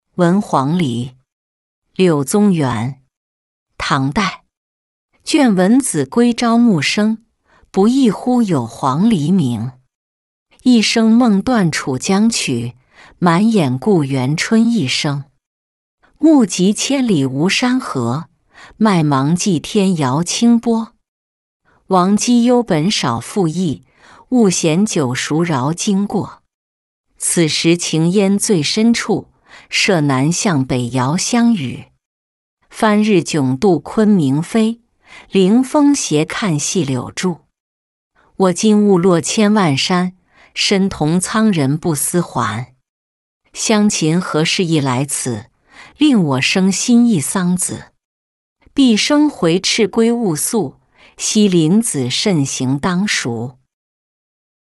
闻黄鹂-音频朗读